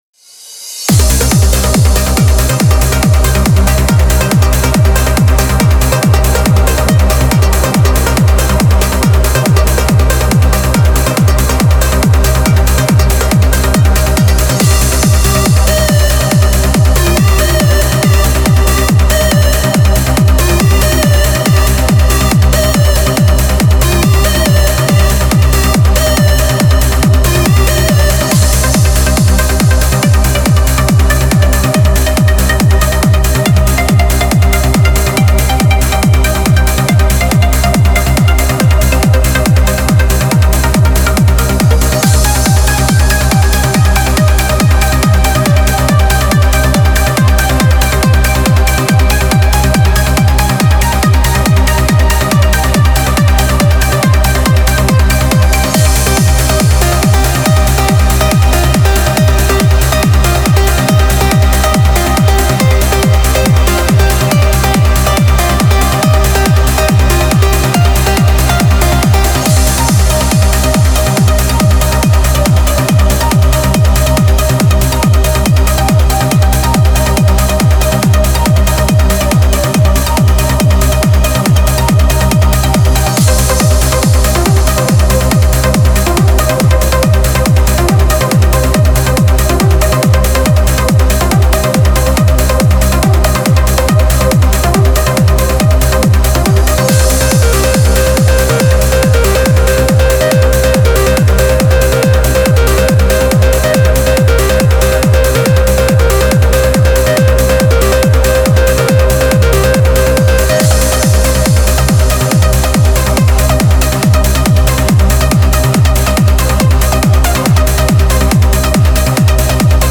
Type: Spire Midi
Trance Uplifting Trance
It does not contain any eq and side chain
Style: Trance, Uplifting Trance